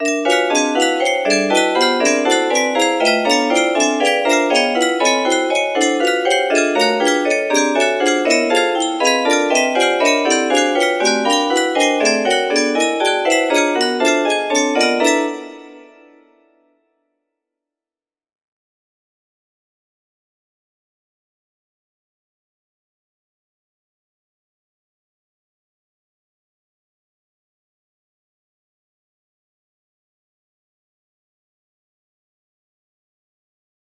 P24 music box melody